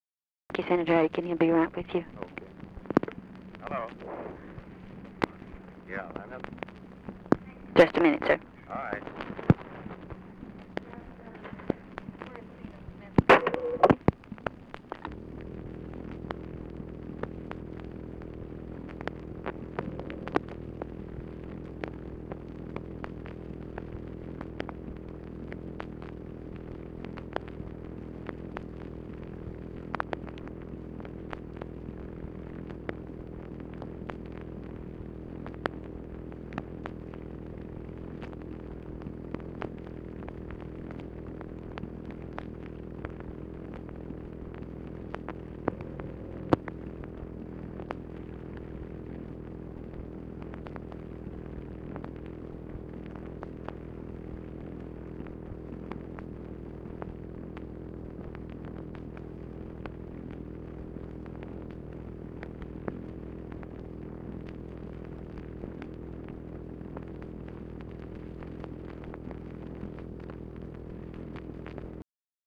Conversation with GEORGE AIKEN, November 27, 1963
Secret White House Tapes | Lyndon B. Johnson Presidency